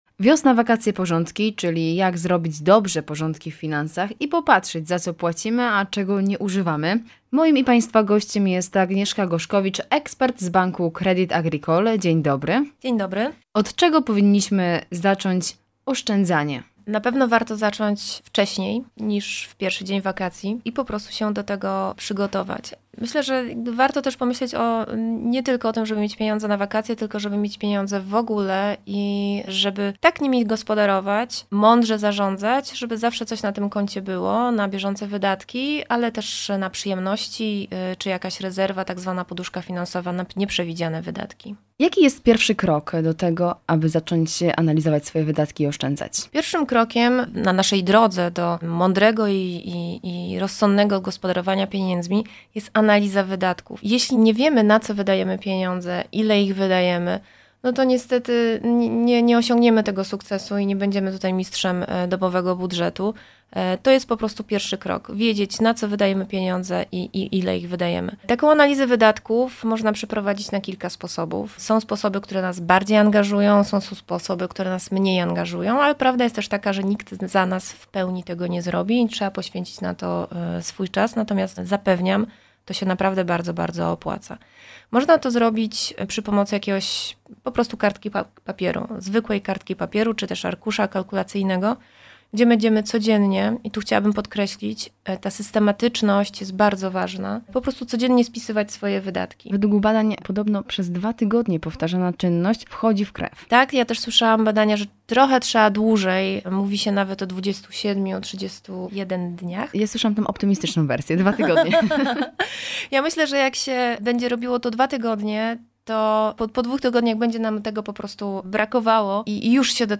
Jak mądrze oszczędzać? Rozmowa